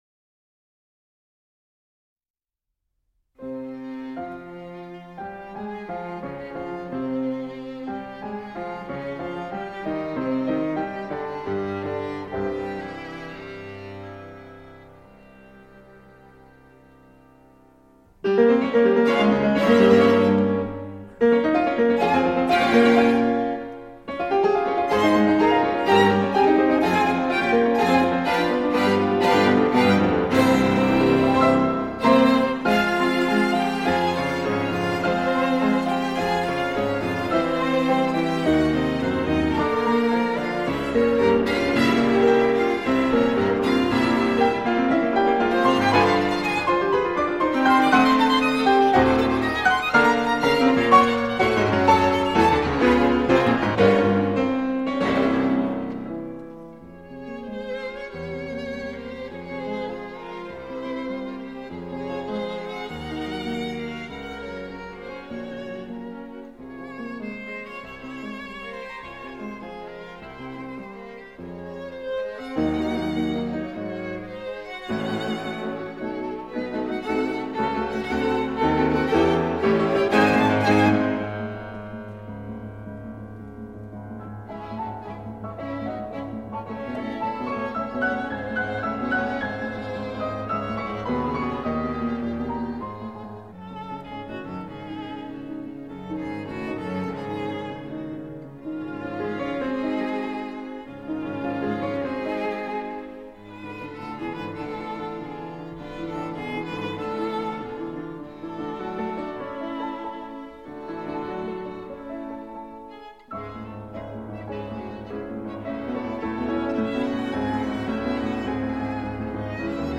Allegro non troppo